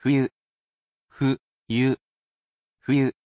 I am sure to read these aloud for you as not to leave you without a way to use them, sounding them out moji by moji.